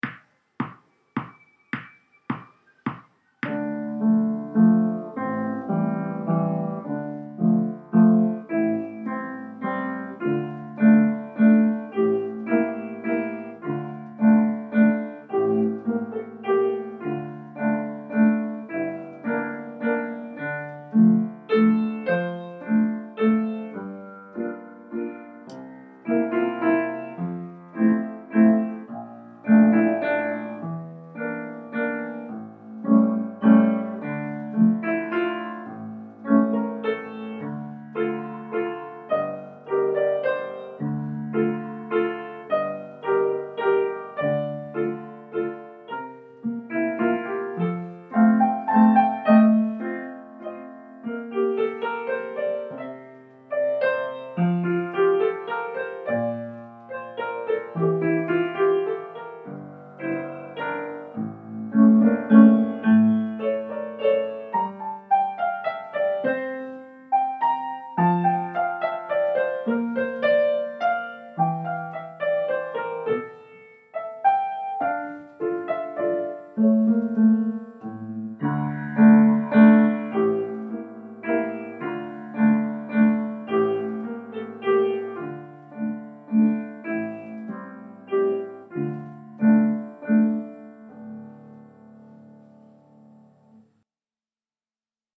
Waltz Piano Only